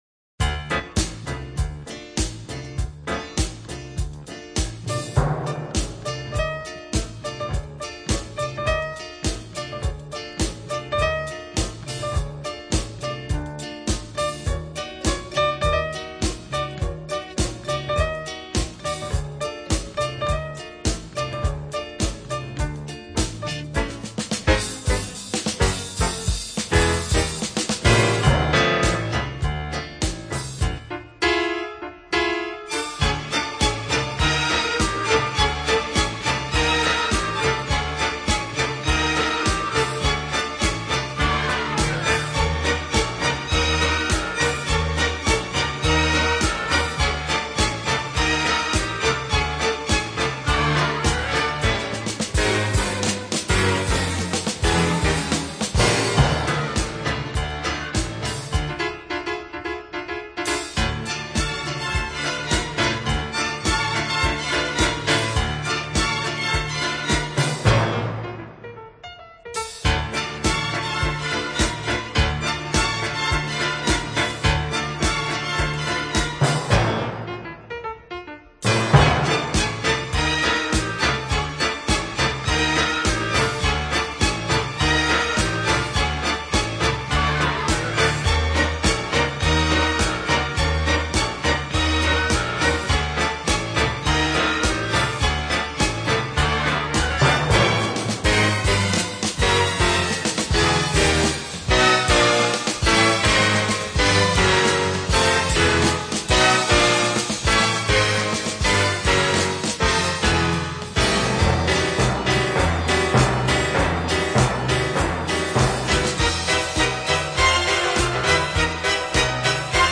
Жанр: Instrumental / Easy Listening